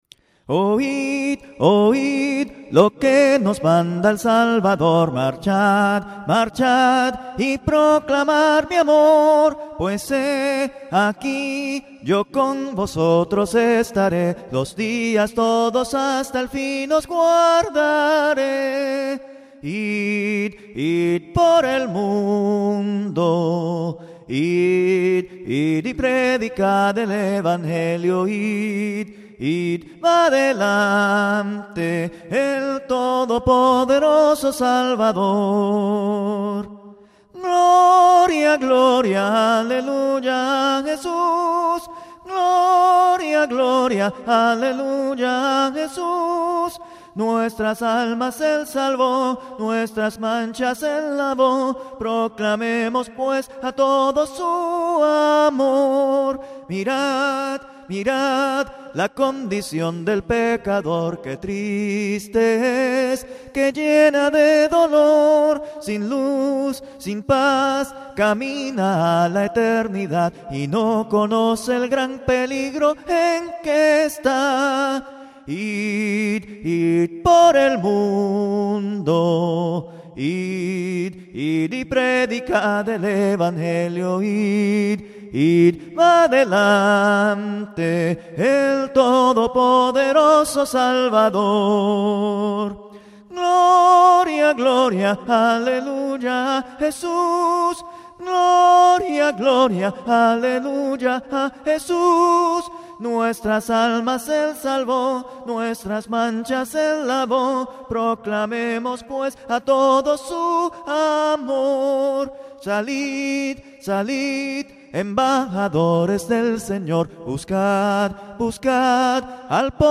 Cánticos Cristianos A Cappella, Gratis
Con Múltiples Voces: